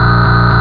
1 channel
buzz2.mp3